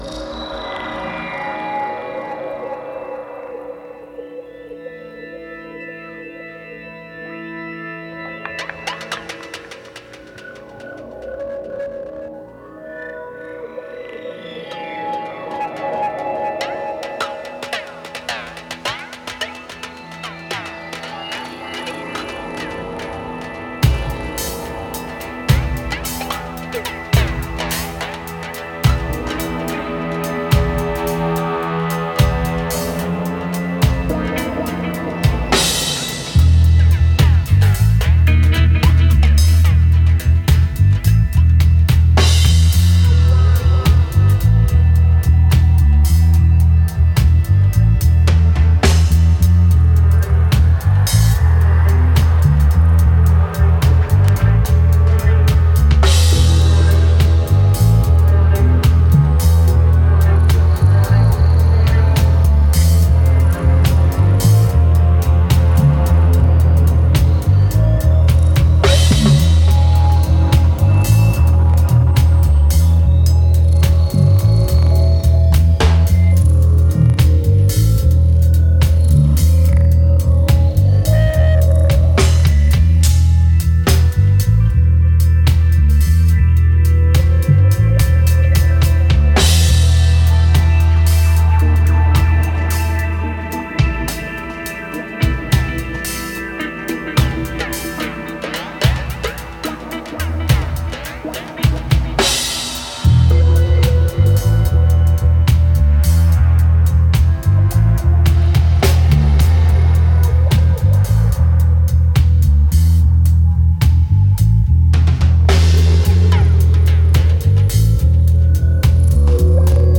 Genre: Dub.